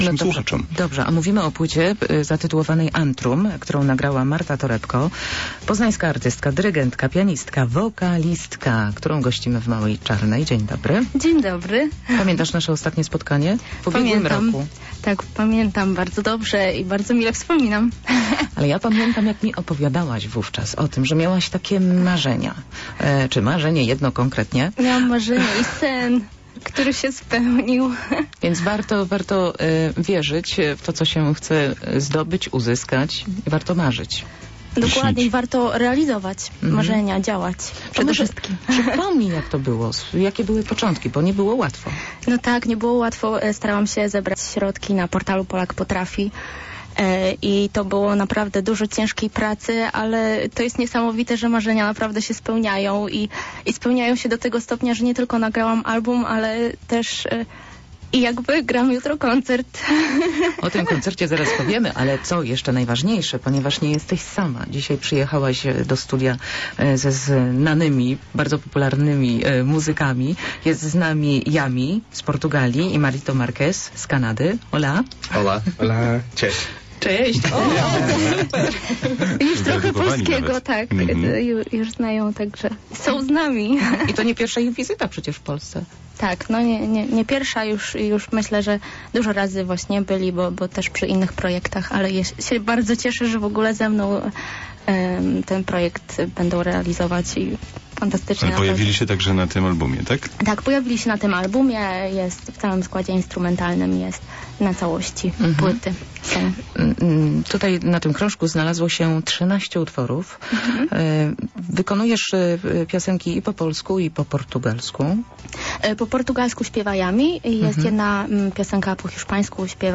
Do studia "Małej Czarnej" zawitali egzotyczni goście naprawdę zakochani w muzyce!
Wspólnie zaśpiewali piosenkę "Tropical".